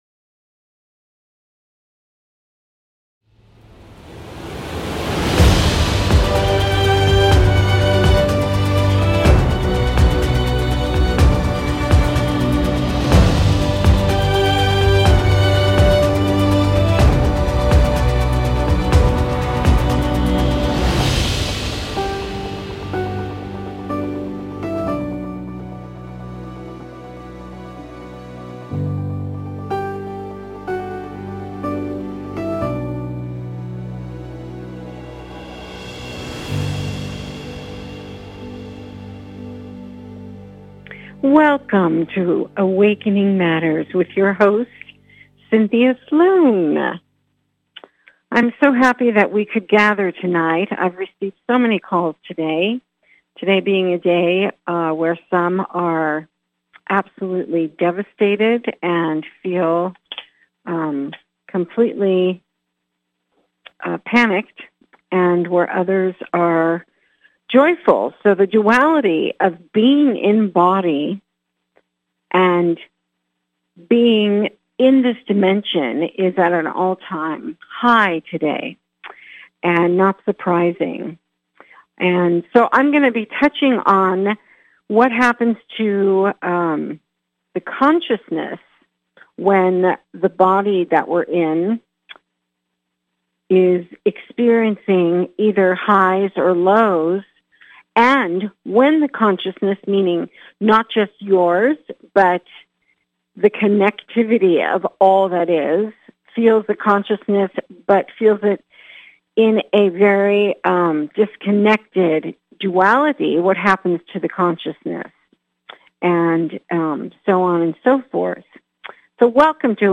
A spiritual dialogue that invites divine wisdom, joy and laughter.